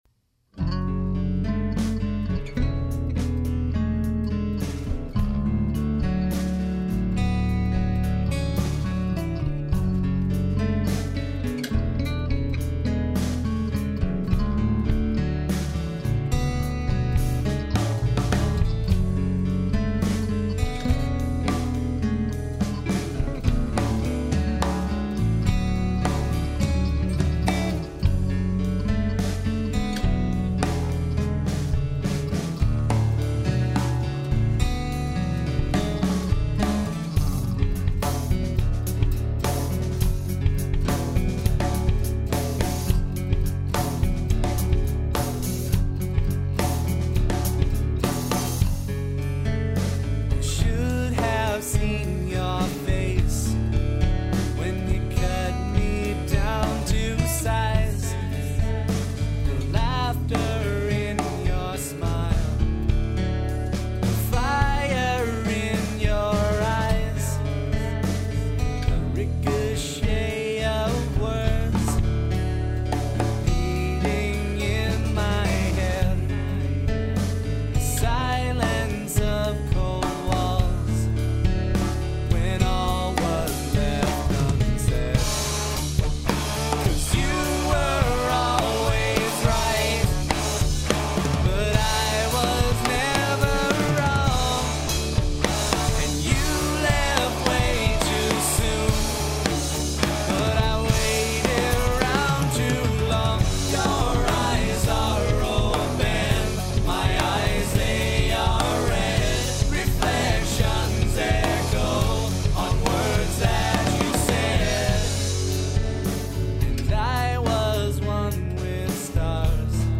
modern rock quartet